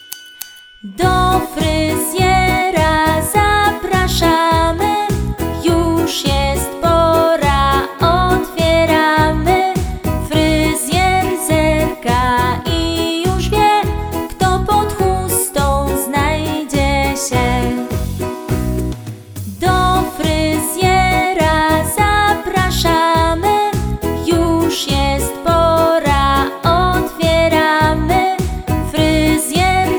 utwór w wersji wokalnej i instrumentalnej